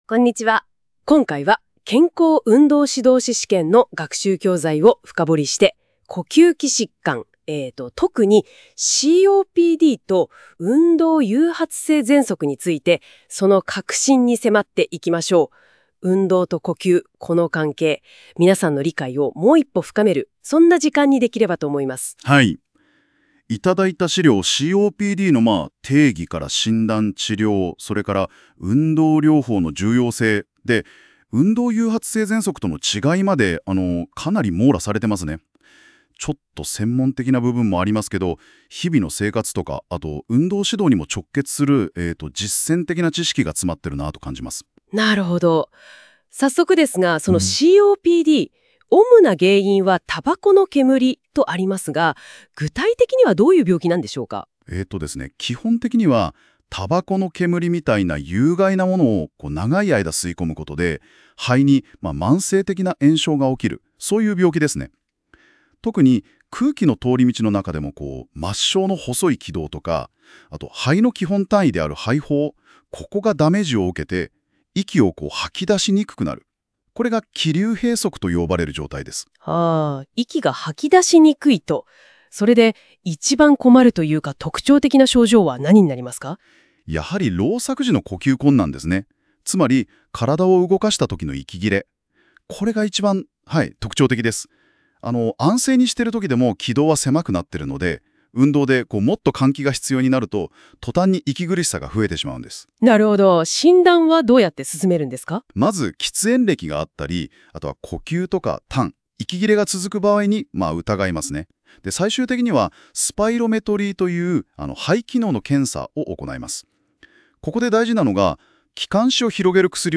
音声で学ぶ、資格試験対策。
健康運動指導士テキスト第3章　3-9呼吸器疾患(COPD、EIA)の音声学習教材です。